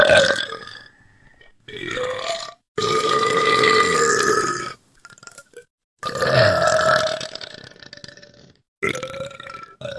Выдавливает из себя отрыжку:
burping3.wav